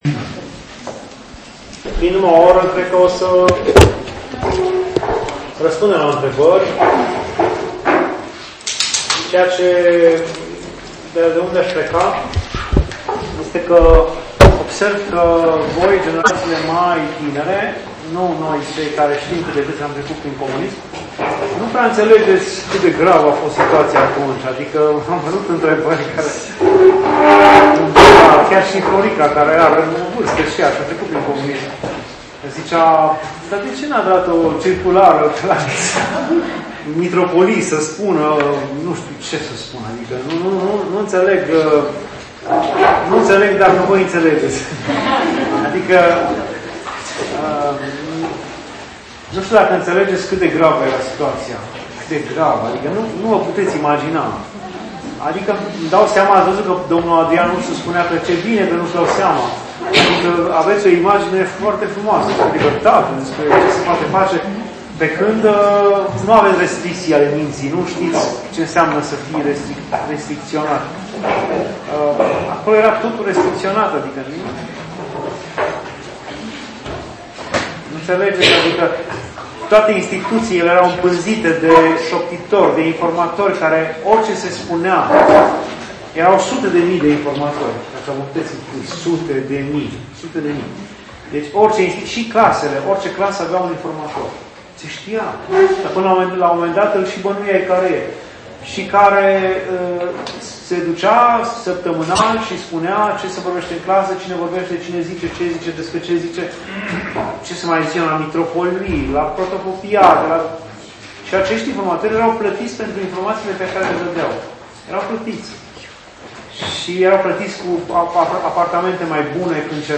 Tabăra ASCOR de la Rogojel - Despre Pr. Arsenie Boca